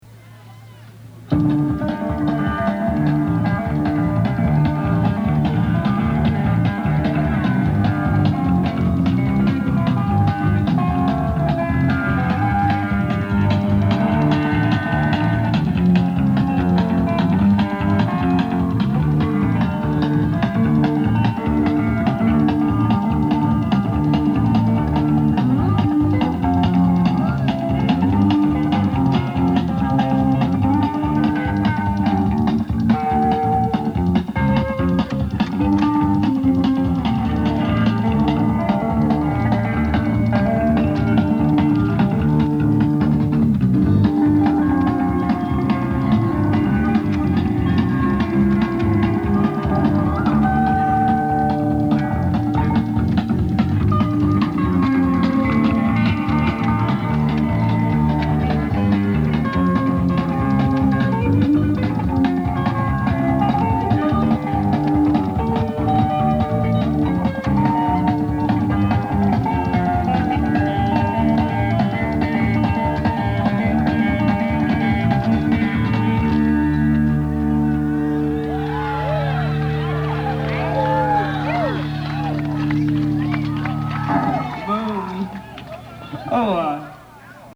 (100 miles east of Victorville, CA)